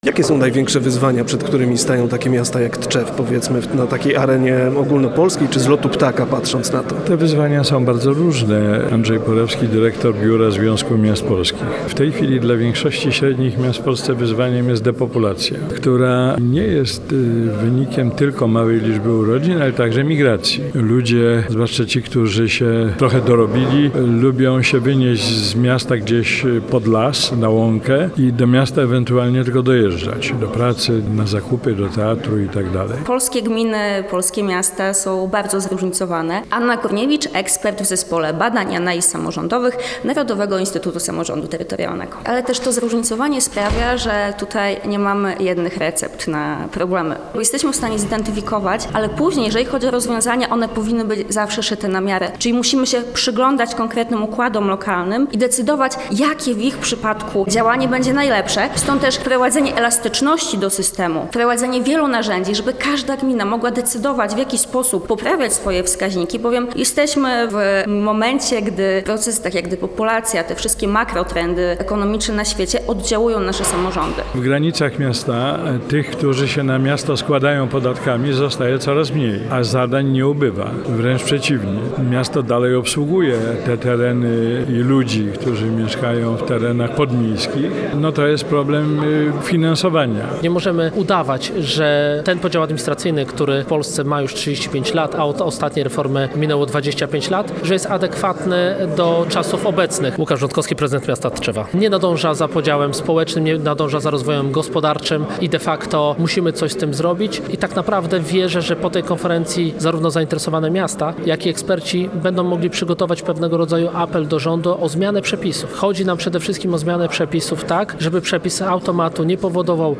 Brak narzędzi prawno-finansowych utrudnia zmiany granic miast w Polsce – to jeden z wątków poruszanych w trakcie konferencji dotyczącej efektywności podziału administracyjnego, która odbywa się w Tczewie.
Posłuchaj relacji naszego reportera: